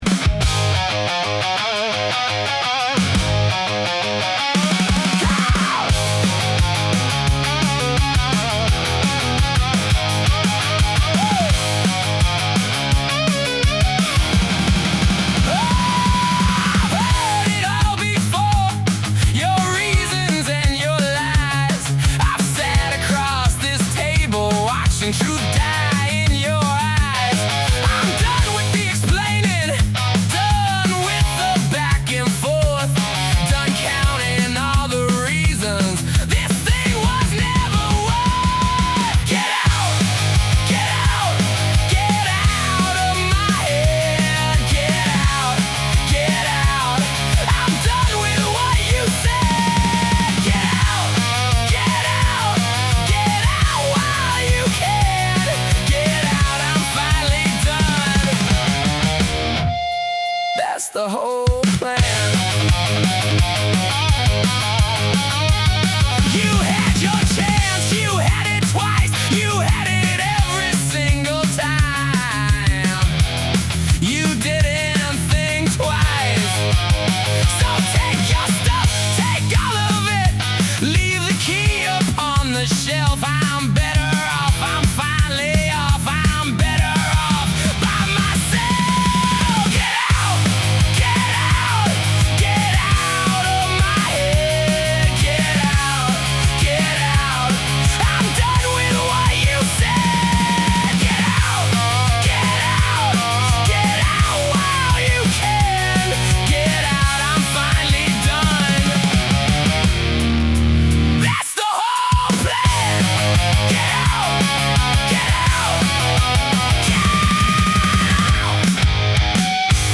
Made with Suno
garage punk, hard rock, punk rock